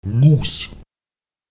Lautsprecher nus [Nus] 86 („Million“)